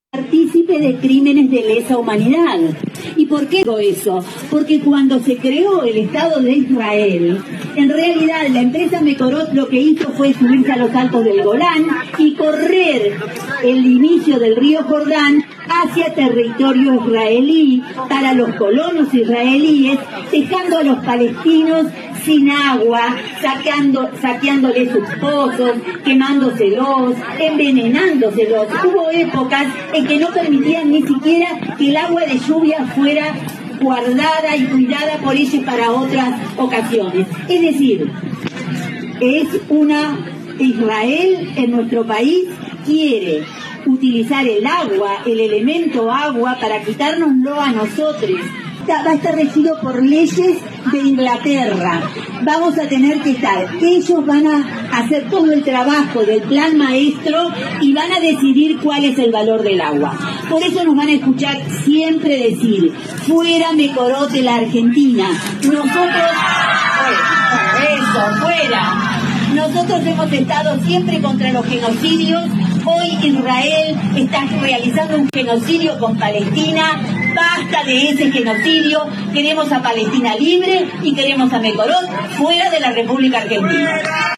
Compartimos las voces de un nuevo Atlanticazo realizado en Viedma que tuvo como consigna principal el rechazo al RIGI y a los proyectos megamineros y petroleros que impulsa Weretilneck y la provincia junto a los municipios
A continuación compartimos un extracto de los testimonios que se escucharon durante el festival artístico musical en el auditorio Guernica de la costanera.